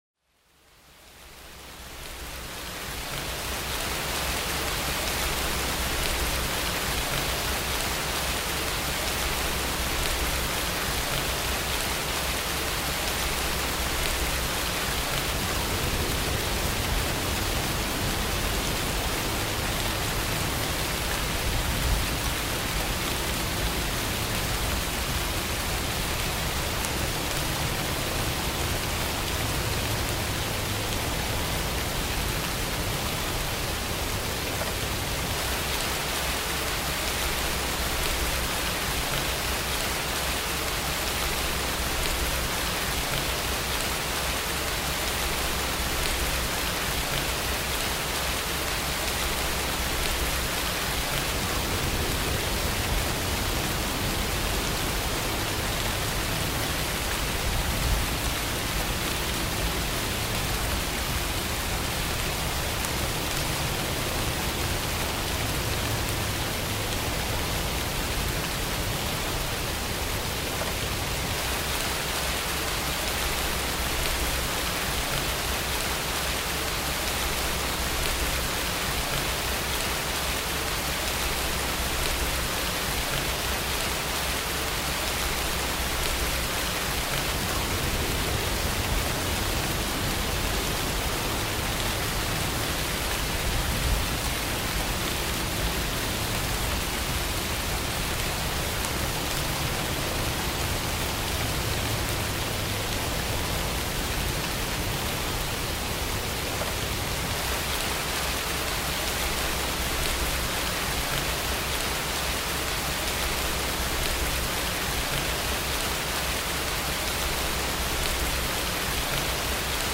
רעש לבן.
רעש לבן 10 שעות עוזר להרדם גשם (0.0s - 336.5s).mp3